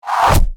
Download Arrow sound effect for free.
Arrow